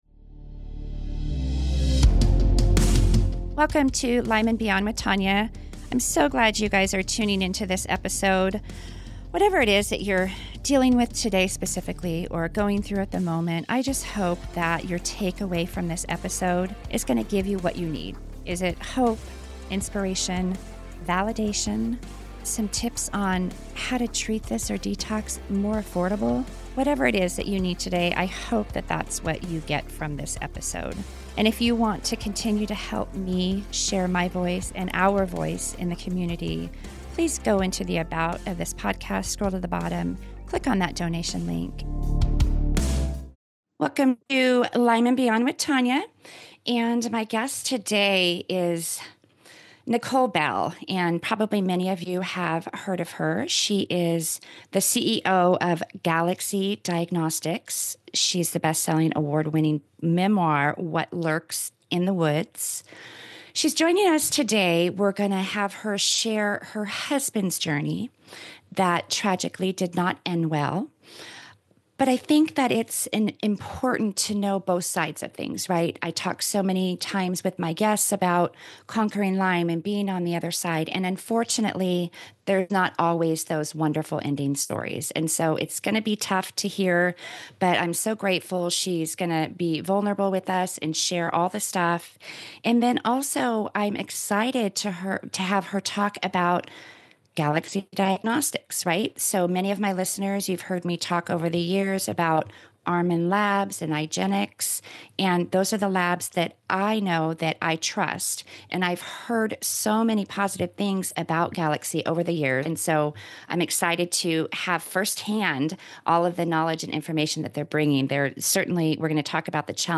The Unseen Journey: A Conversation